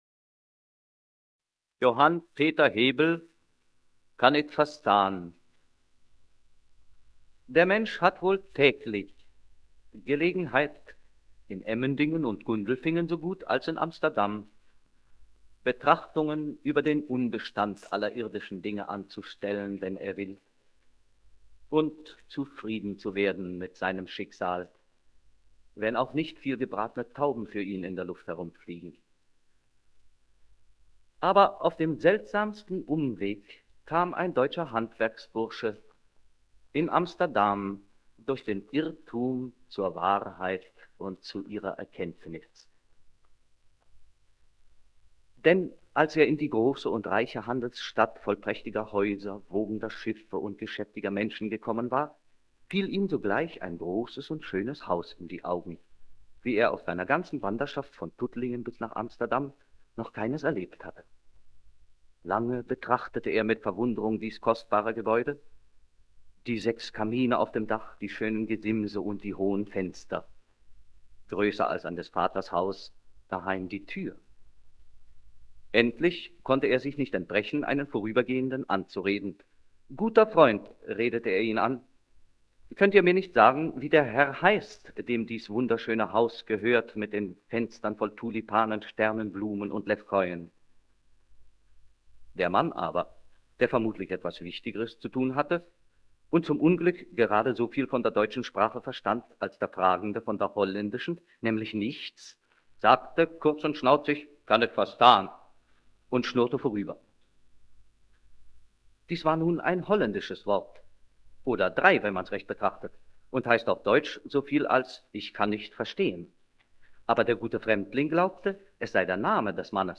Aufnahme / Schallplatte ca. 1930; Odeon O-4773 -a & -b.